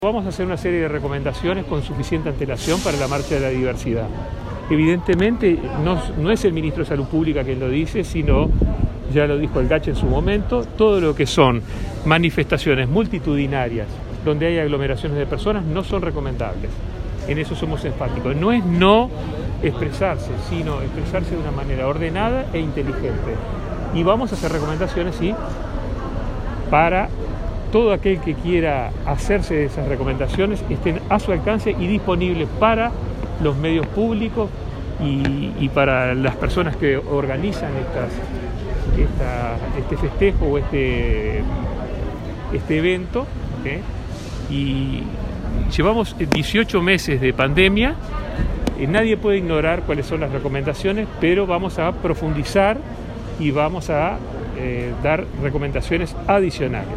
El ministro de Salud Pública, Daniel Salinas, dijo que harán «una serie» de recomendaciones para la marcha de la diversidad.